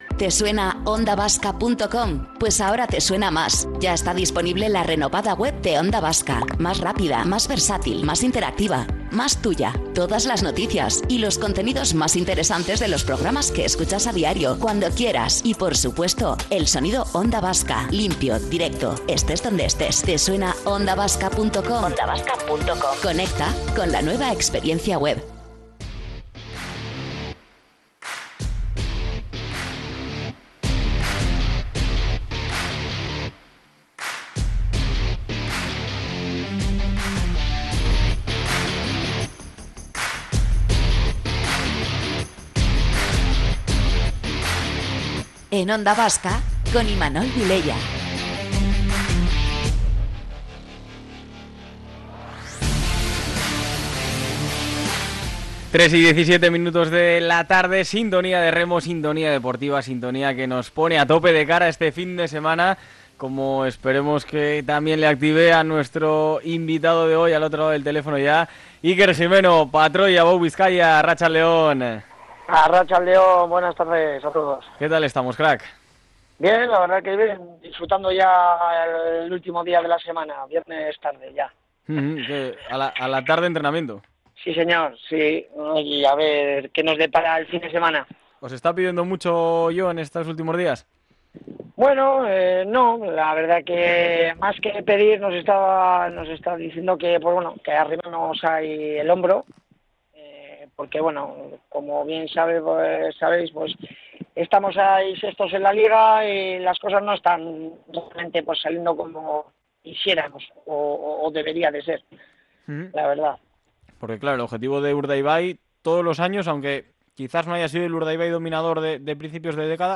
Entrevistas deportivas